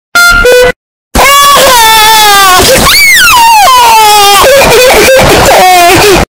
George Crying Earrape - Bouton d'effet sonore